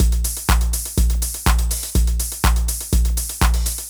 ENE Beat - Mix 2.wav